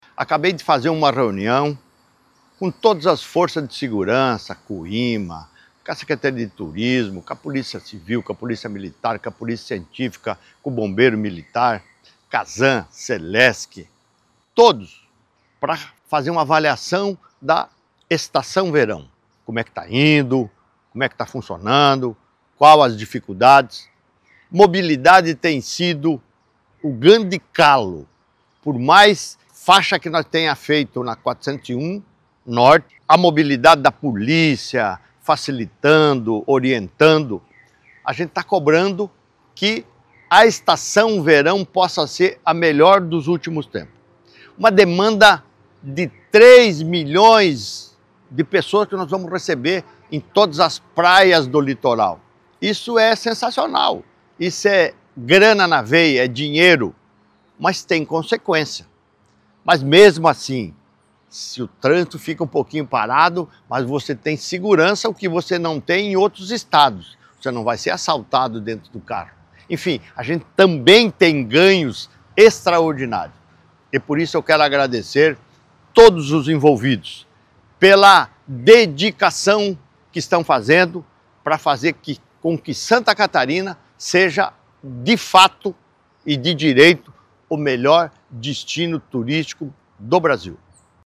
O governador agradeceu o desempenho de todos e cobrou ainda mais esforços para fazer do estado o melhor destino turístico do Brasil:
SECOM-Sonora-governador-avaliacao-Estacao-Verao.mp3